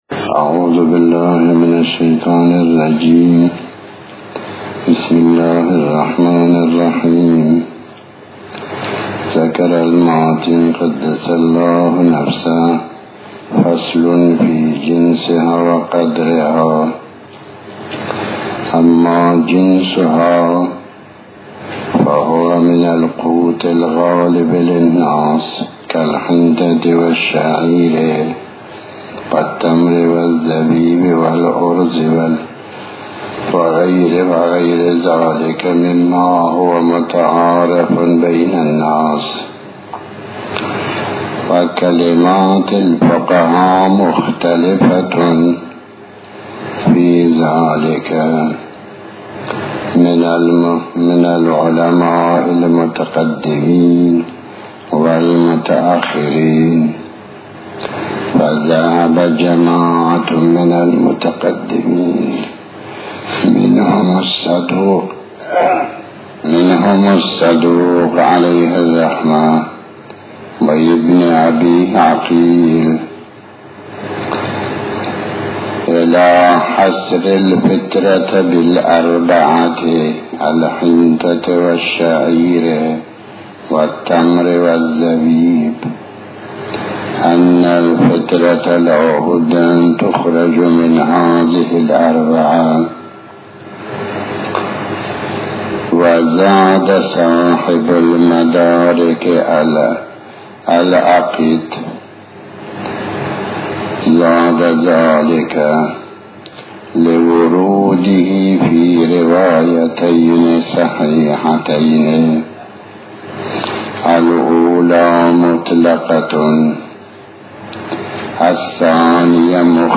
تحمیل آیةالله الشيخ محمداسحاق الفیاض بحث الفقه 38/07/19 بسم الله الرحمن الرحيم الموضوع:- زكاة الفطرة.